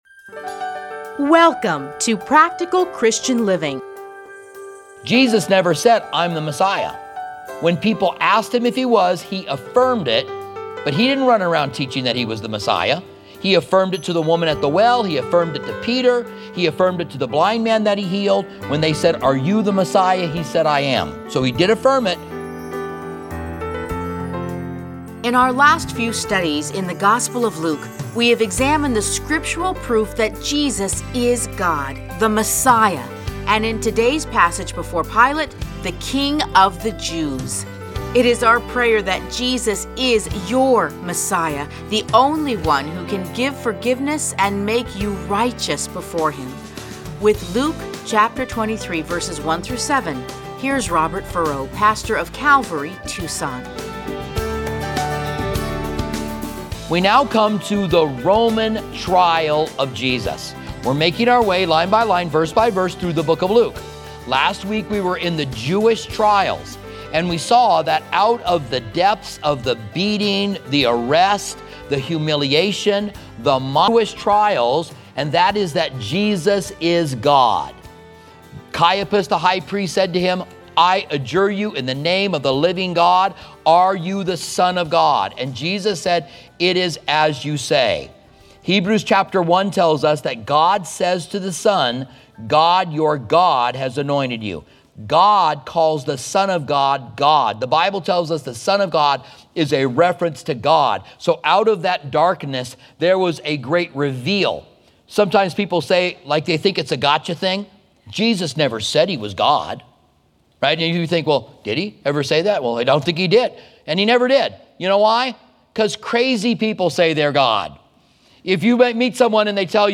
Listen to a teaching from Luke 23:1-7.